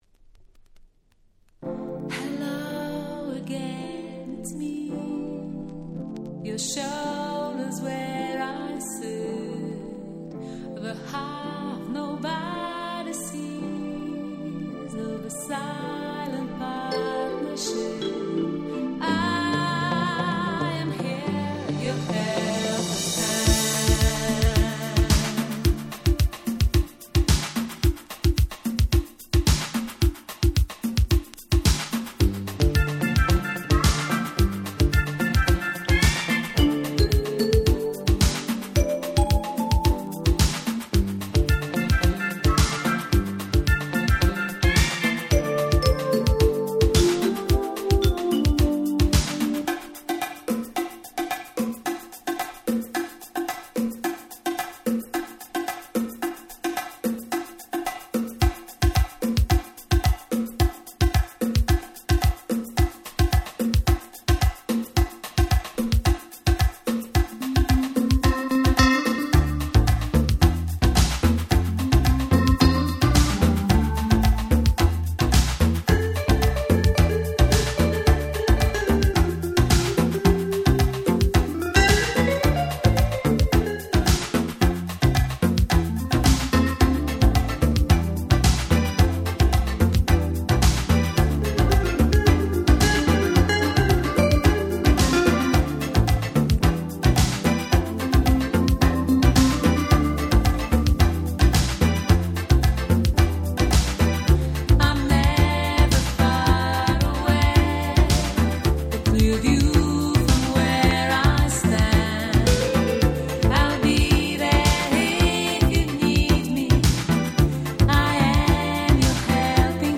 軽快でキャッチーな80's Popsナンバーです。